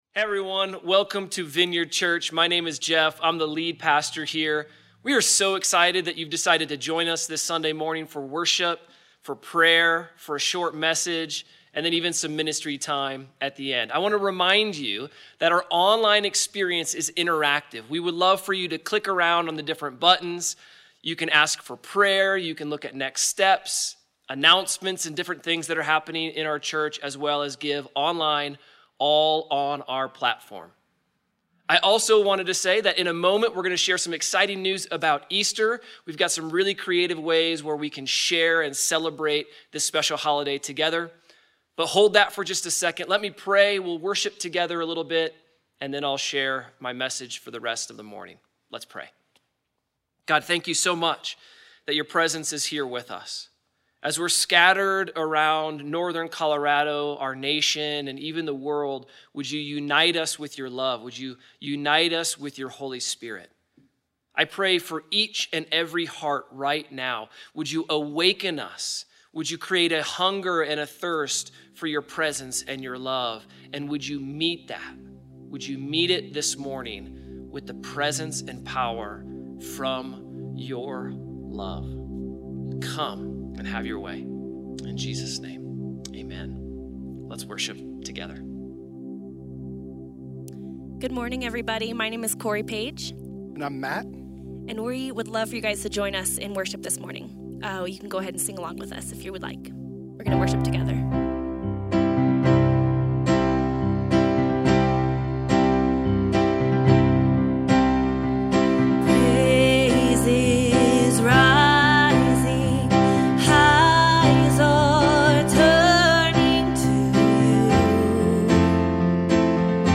Sermon-MP3.mp3